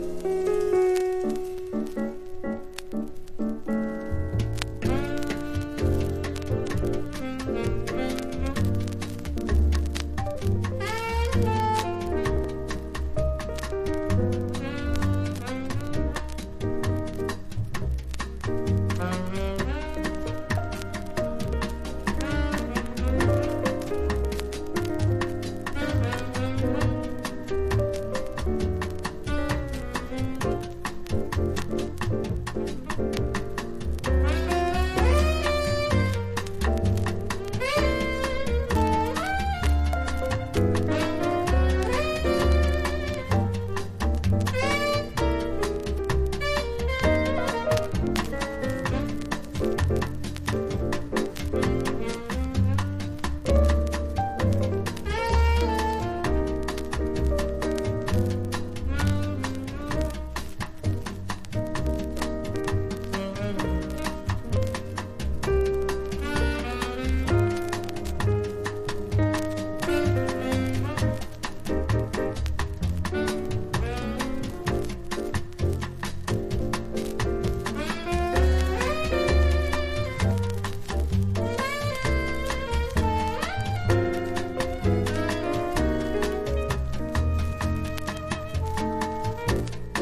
# 和モノ / ポピュラー# 90-20’S ROCK